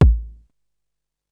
• 2000s Long Steel Kick Drum A Key 388.wav
Royality free bass drum single hit tuned to the A note. Loudest frequency: 206Hz